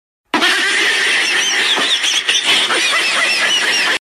Dog Laughing